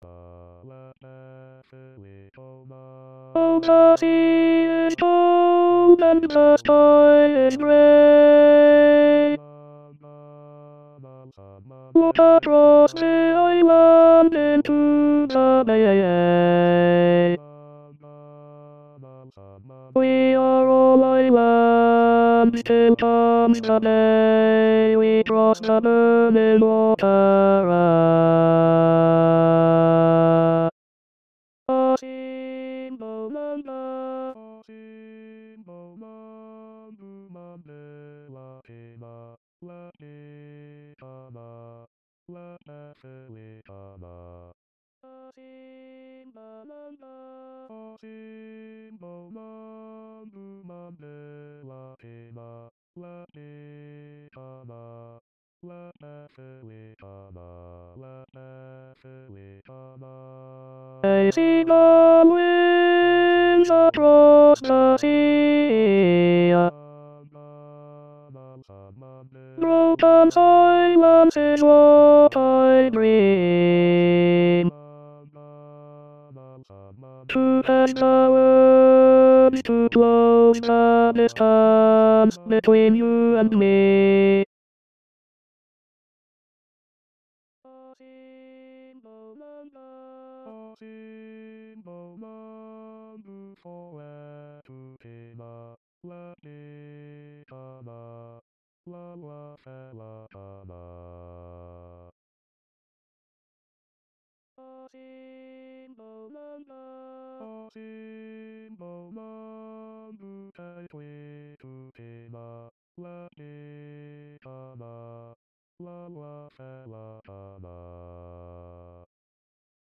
Asimbonanga soliste et haute contre.mp3